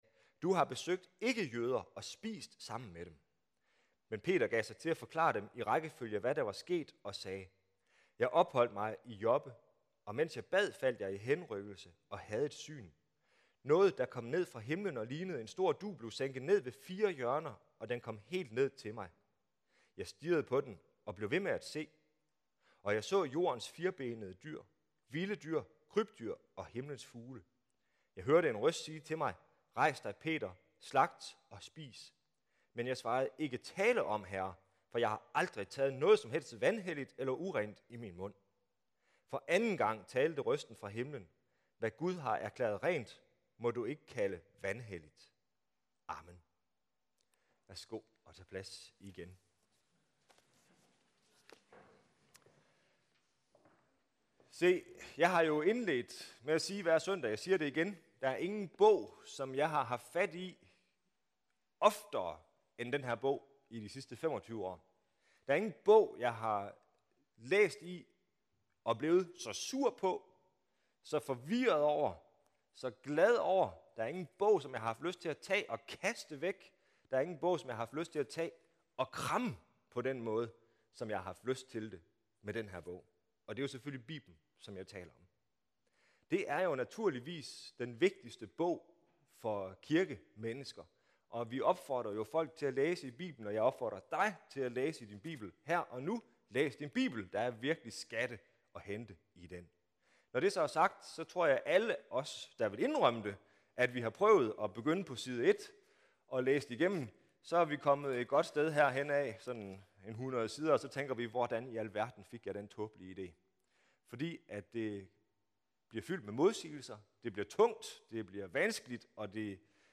Gennem de næste fem uger er temaet for gudstjenesterne “Læg arm med Bibelen.”